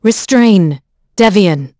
Isolet-Vox_Skill6.wav